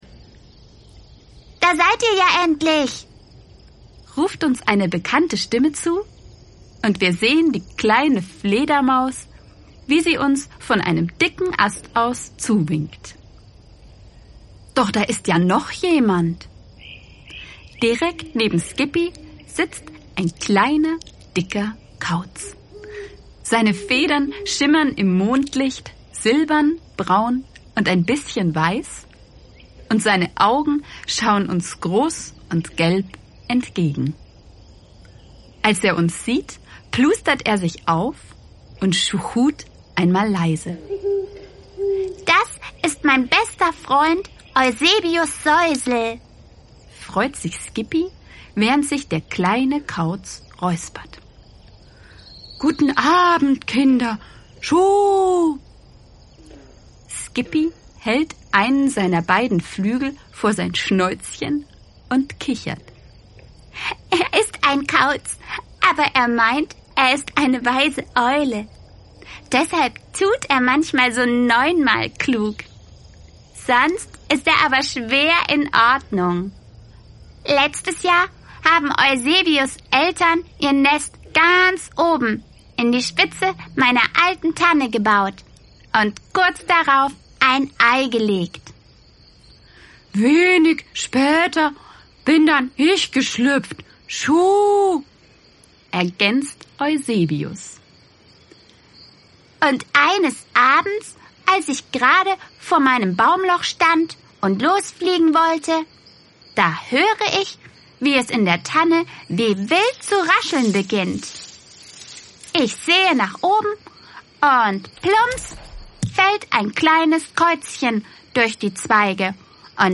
Hörbuchdebut für Groß und Klein